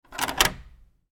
Old-key-lock-unlocking-locking-sound-effect.mp3